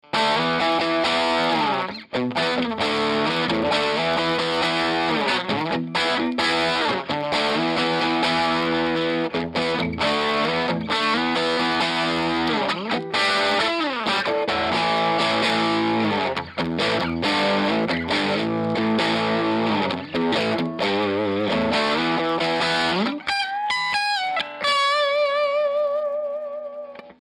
I recorded it using a Lambda pre-box and Shure SM57.
The guitar is a faded les paul double cut with old(ish) P-90's. It is a recent acquisition and sounds very nice.
The guitar was plugged into the bright channel input 2.....the volume was pretty much cranked with the treble on 8, mids on 3-4 and bass on 0.
I Have a Marshall bluesbreaker RI and it does not have the bold strident tone or chunky bottom that the Trinity has.
The SM57 was right at the grillcloth at the Alnico Tone Tubby.